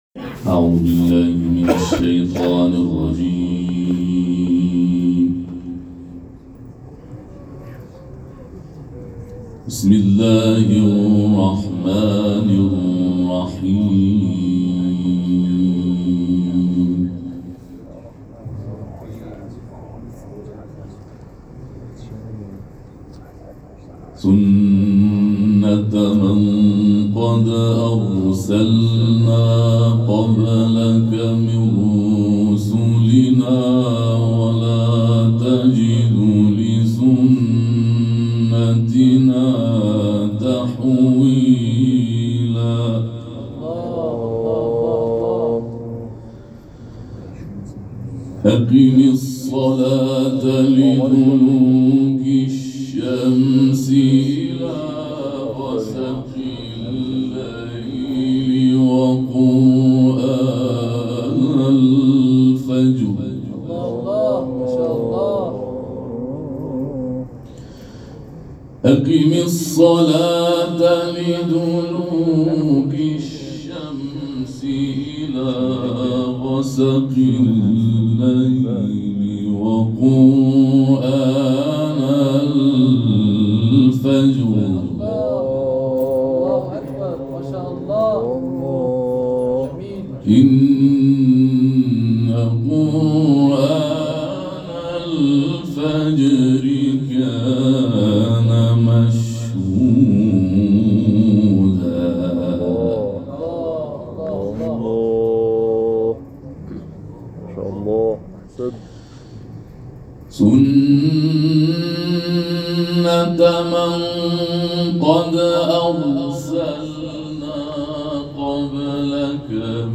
نماینده ولی فقیه در استان البرز در دیدار قاریان و حافظان ممتاز با تأکید بر اینکه آیات قرآن نور و هدایت است و هرچه بیشتر پیش برویم این هدایت بیشتر خواهد شد، گفت: شما که با قرآن انس دارید، سعی کنید دائماً از هدایت قرآن بهره ببرید و فقط هم این‌چنین نباشد که به قرائت و حفظ قرآن بسنده کنید بلکه باید به فهم عمیق قرآن دست پیدا کنید.